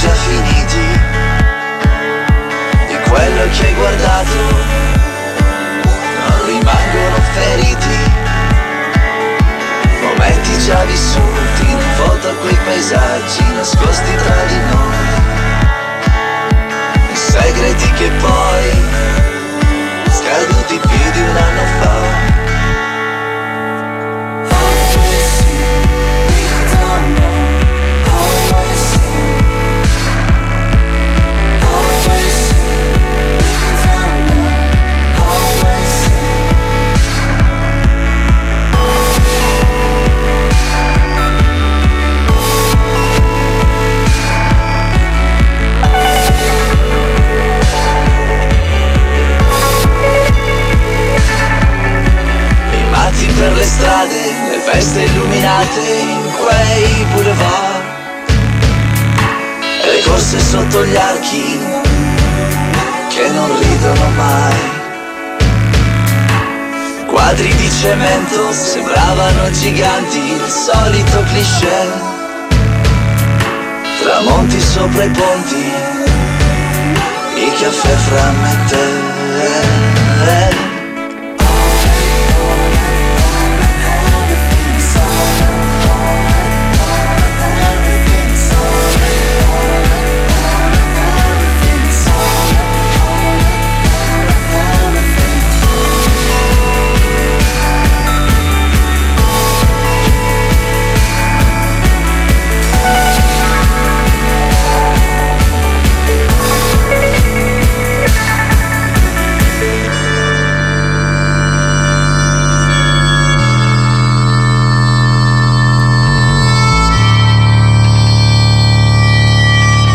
In effetti questo pensiero è diventato realtà, con RADIO ONDA ROSSA infatti ho creato un programma in cui adulti protagonisti, attori di varie generazioni, interpretano una favola per gli ascoltatori più piccini, per i bambini che molto spesso insieme alle loro mamme sono privati della loro libertà personale, o che sono costretti a trascorrere la maggior parte del tempo chiusi dentro casa, o in un letto d'ospedale con, come unica alternativa alla noia, la televisione.